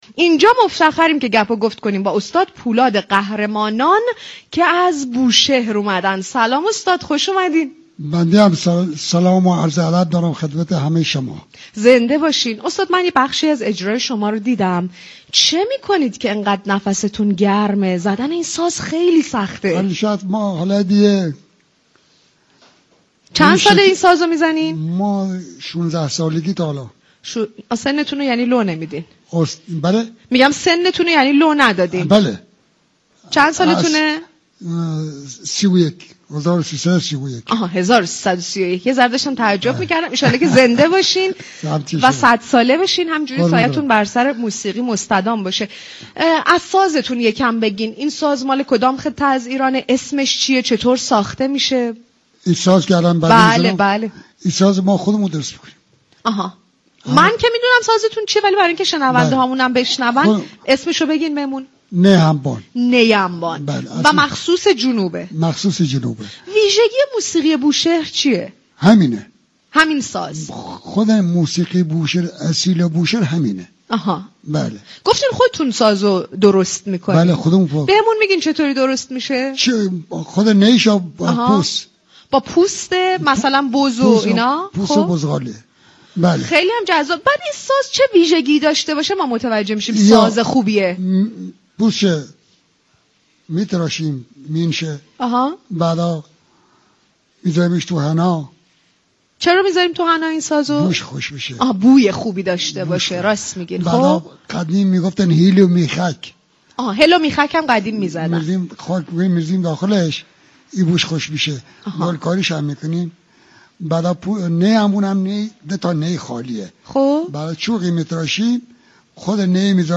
«صباهنگ» از شنبه تا دوشنبه ساعت 18:30 از محل برگزای این جشنواره در تالار رودكی پخش می شود.
علاقه مندان می توانند با مراجعه به سایت رادیو صبا شنونده این گفتگو باشند.